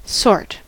sort: Wikimedia Commons US English Pronunciations
En-us-sort.WAV